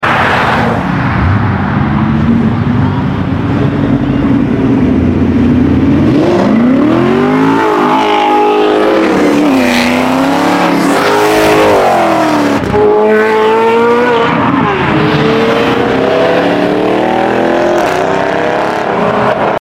Fast and loud V8s leaving sound effects free download
Fast and loud V8s leaving Dayton Cars and Coffee